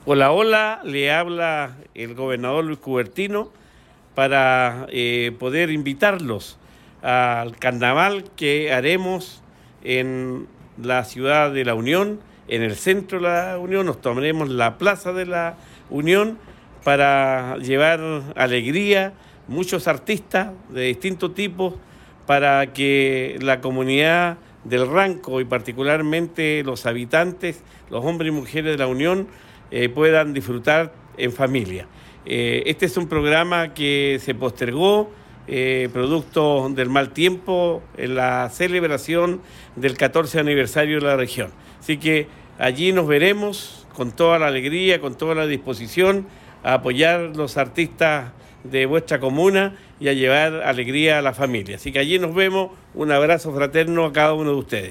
Gobernador_Carnaval-Ciudadano_La-Union.mp3